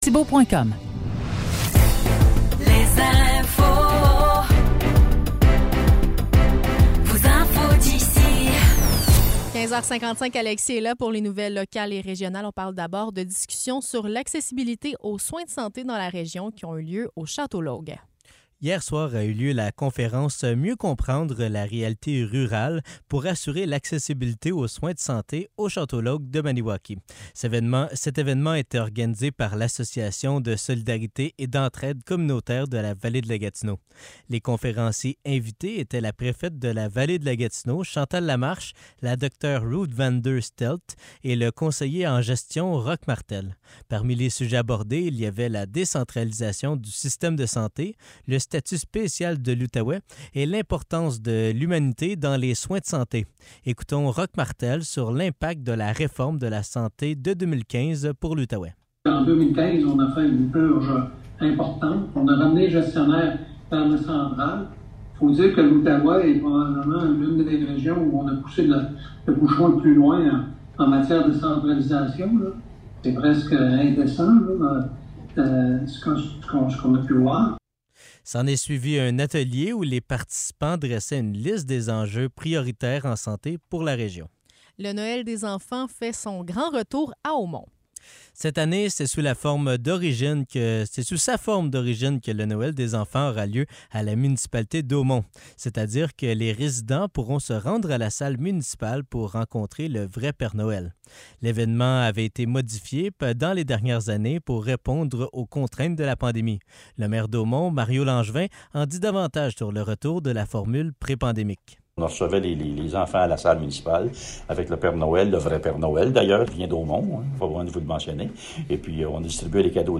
Nouvelles locales - 10 novembre 2023 - 16 h